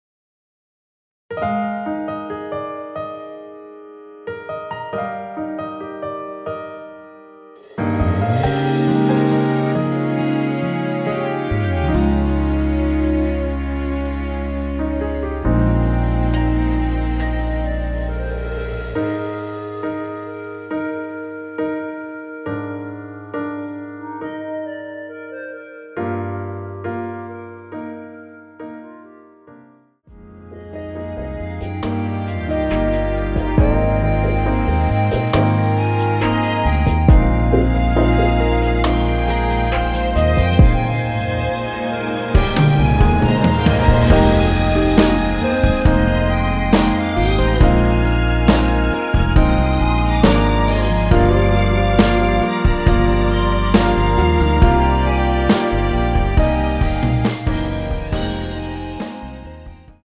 원키 멜로디 포함된 MR입니다.(미리듣기 확인)
Eb
앞부분30초, 뒷부분30초씩 편집해서 올려 드리고 있습니다.